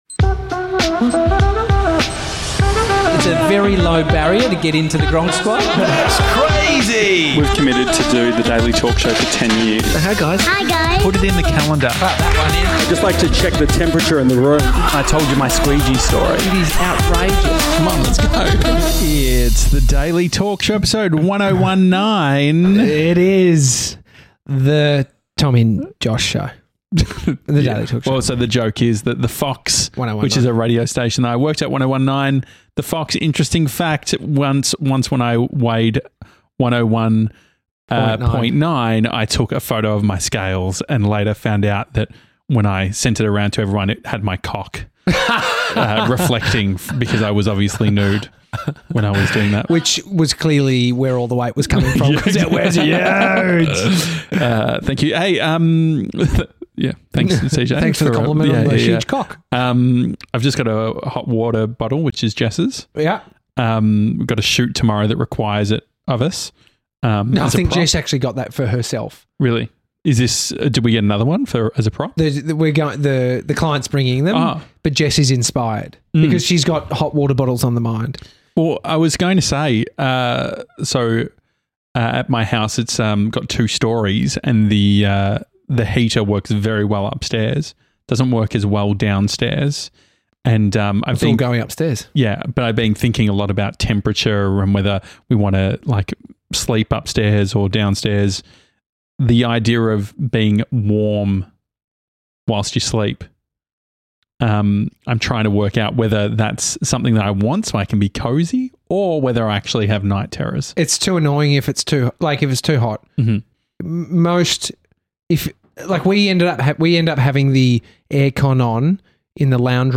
an Australian talk show and daily podcast
Regularly visited by guests and gronks!